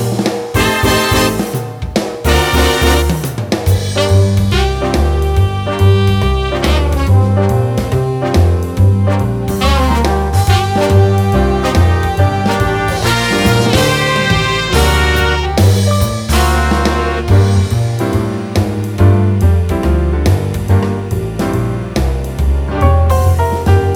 Chord Only Ending Jazz / Swing 3:49 Buy £1.50